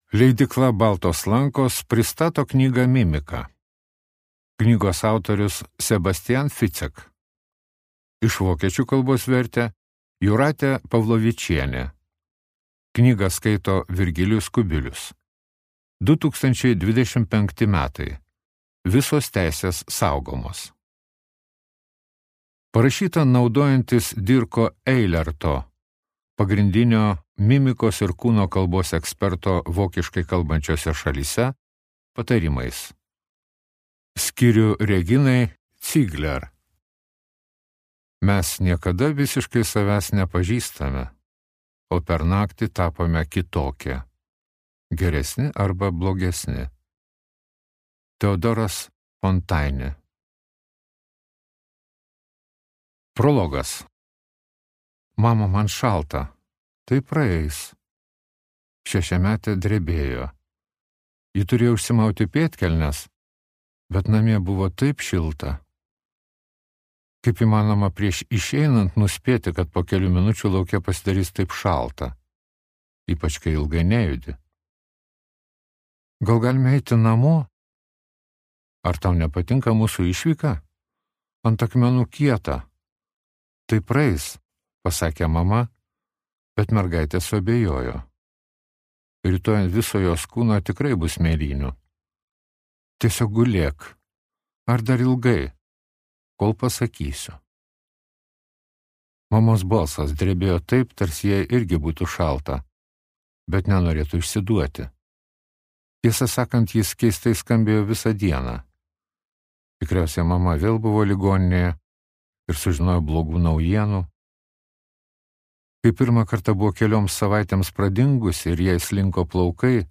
Mimika | Audioknygos | baltos lankos